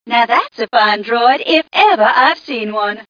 1 channel
mission_voice_ghca051.mp3